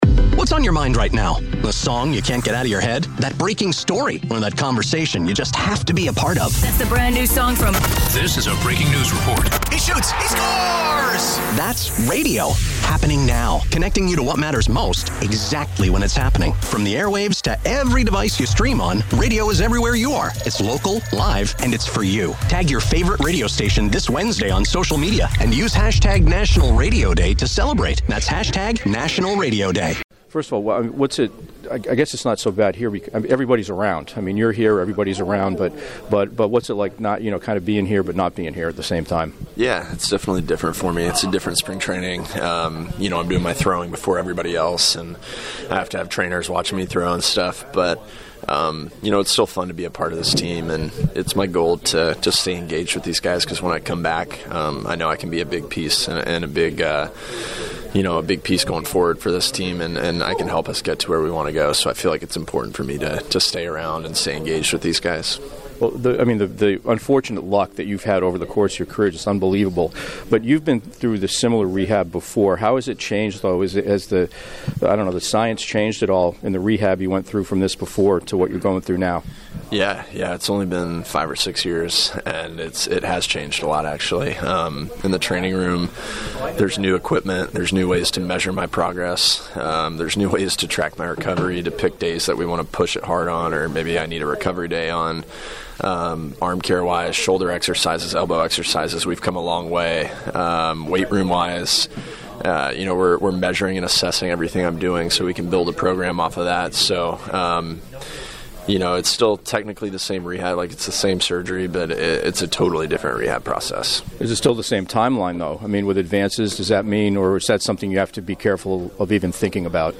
shares his one-on-one interviews with athletes andsports newsmakers in Pittsburgh.